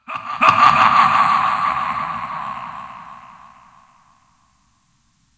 PixelPerfectionCE/assets/minecraft/sounds/mob/wither/hurt1.ogg at mc116